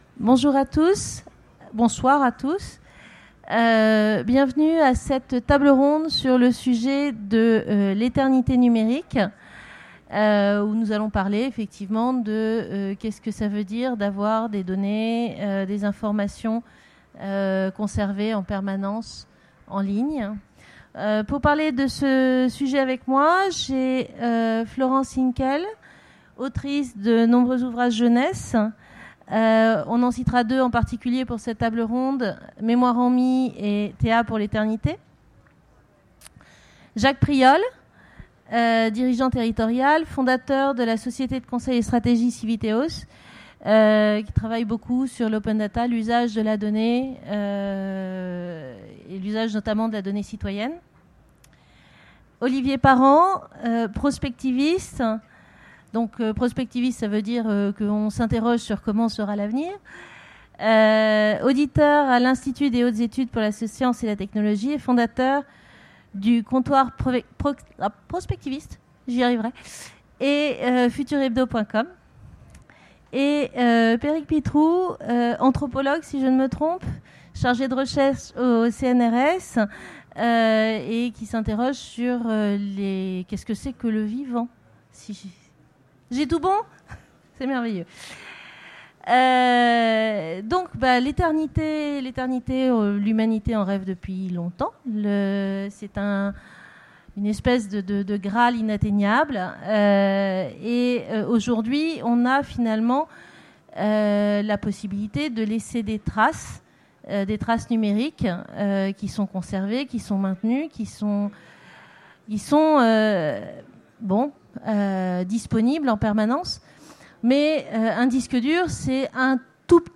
Utopiales 2017 : Conférence L’éternité numérique